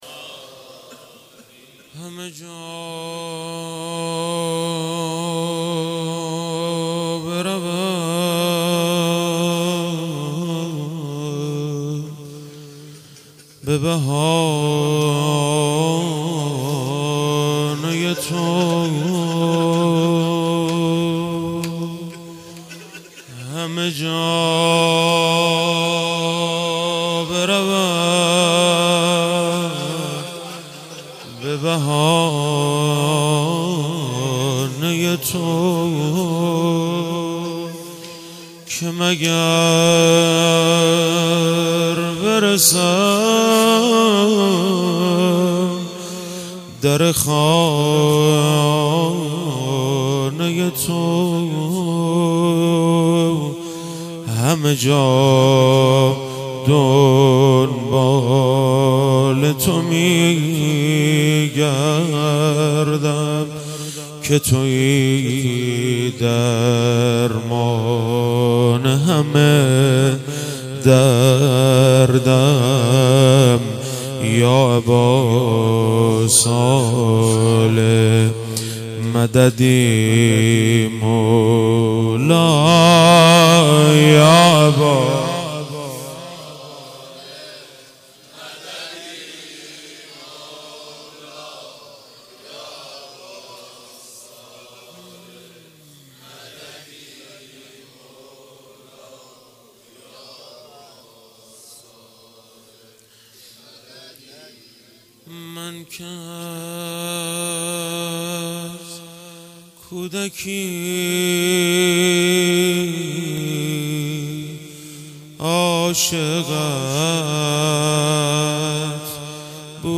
روضه مداحی